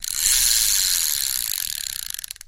Звуки рыбалки
Звук катушки спиннинга